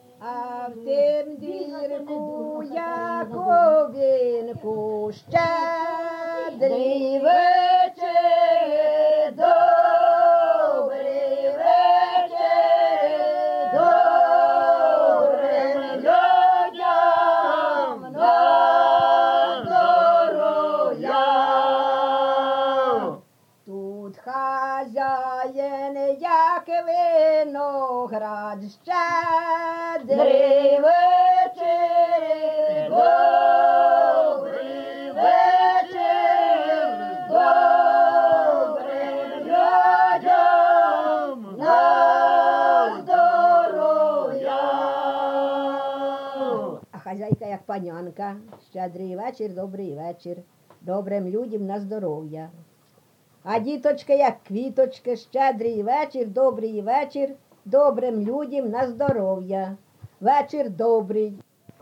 ЖанрЩедрівки
Місце записус. Куземин, Охтирський район, Сумська обл., Україна, Слобожанщина